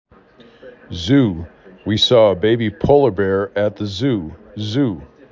3 Letters, 1 Syllable
z U